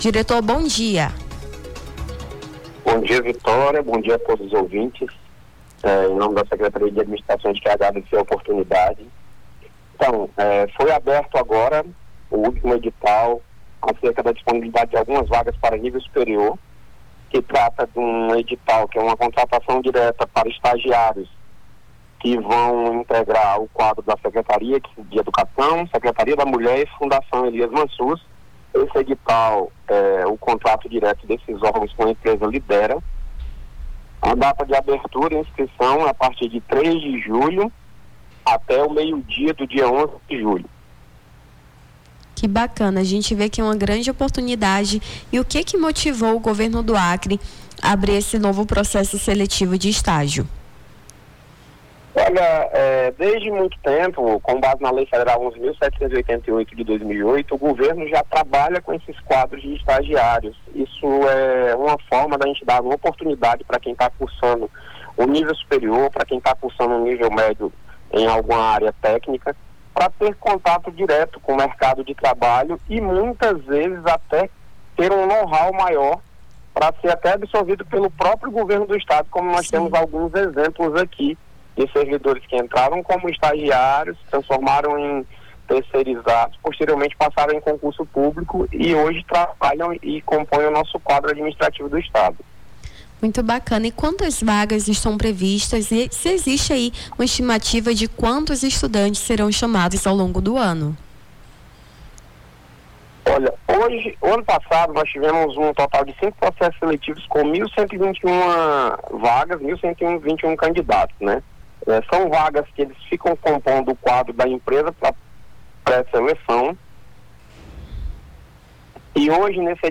Nome do Artista - CENSURA - ENTREVISTA (GOVERNO INSCRIÇÕES ESTAGIARIOS) 03-07-25.mp3